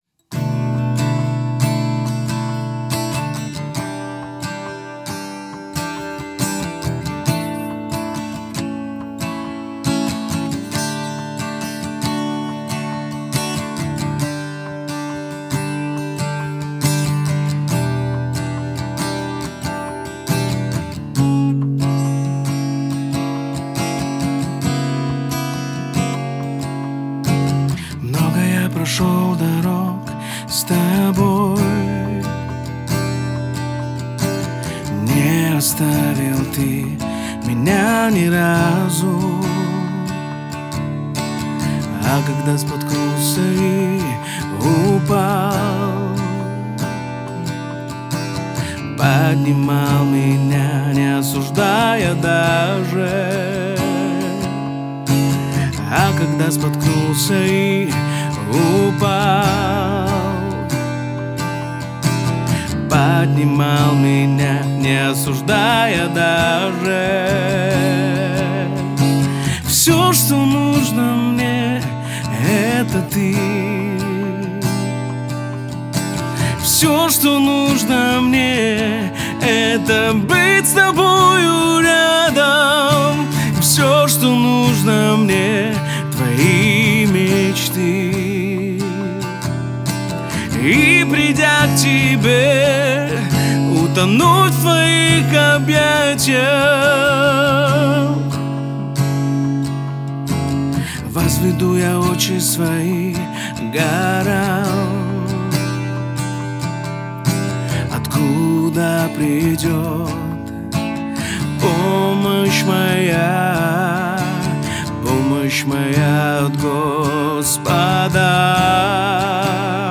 песня
316 просмотров 437 прослушиваний 16 скачиваний BPM: 139